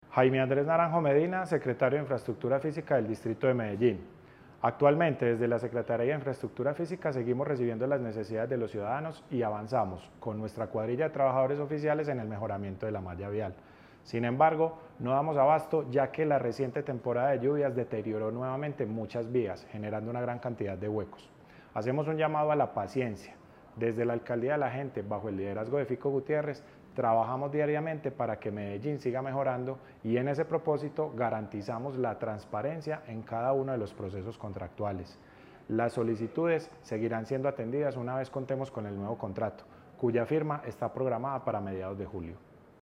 Declaraciones del secretario de Infraestructura Física, Jaime Andrés Naranjo Medina.
Declaraciones-del-secretario-de-Infraestructura-Fisica-Jaime-Andres-Naranjo-Medina..mp3